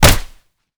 kick_hard_impact_03.wav